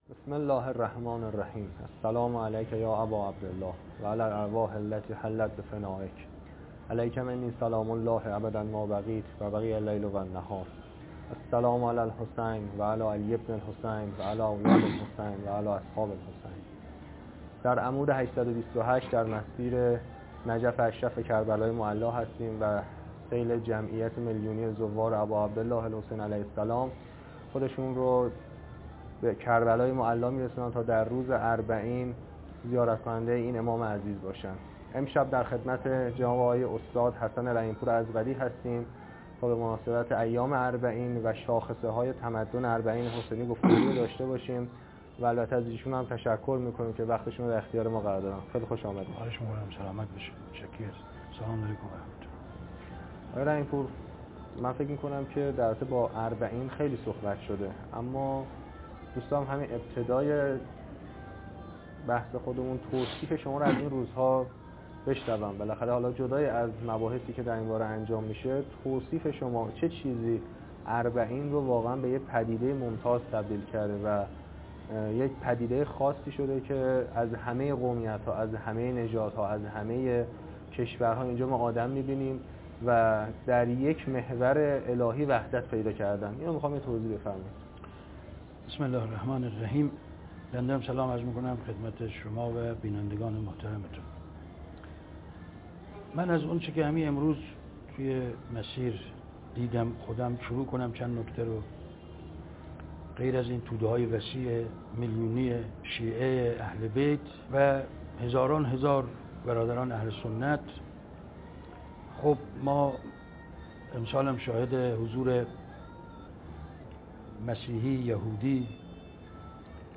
مسیر نجف - کربلا - زیارت اربعین - 1398
مصاحبه با برنامه جهان آرا